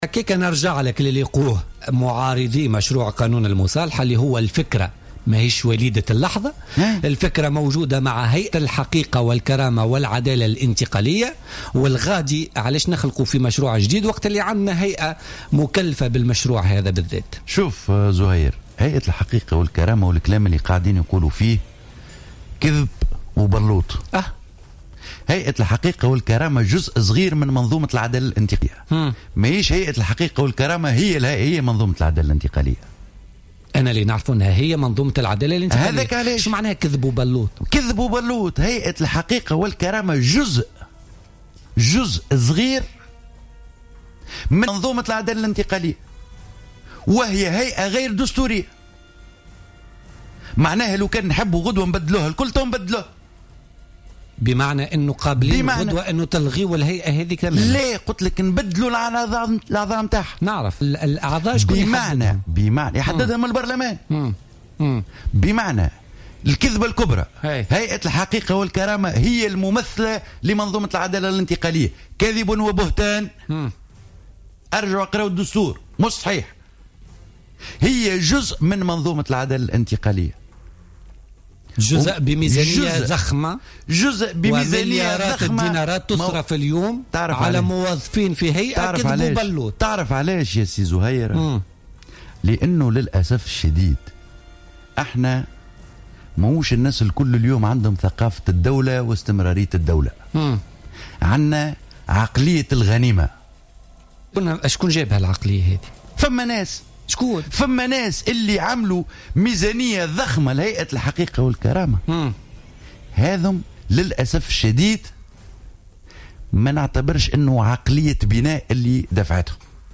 قال نور الدين بن تيشة القيادي في نداء تونس في برنامج بوليتكا لليوم الثلاثاء 29 سبتمبر 2015 أن هيئة الحقيقة والكرامة "كذبة كبرى" وأنها لا تمثل العدالة الانتقالية على حد تعبيره.